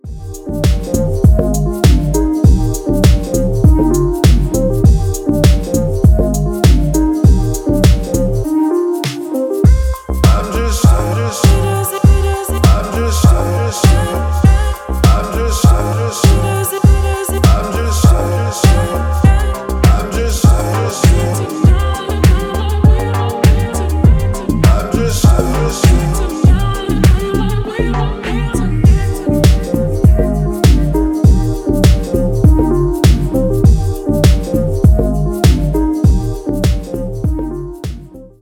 edm
басы